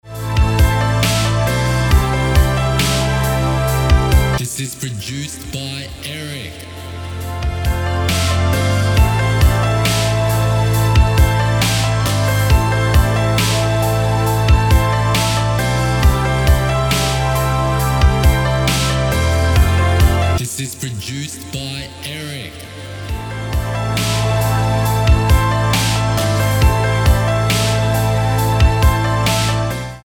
Key: A minor Tempo: 68BPM Time: 4/4 Length: 4:00